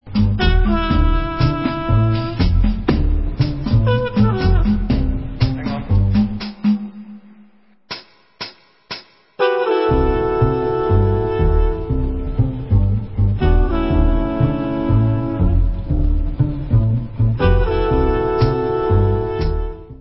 sledovat novinky v oddělení Dance/House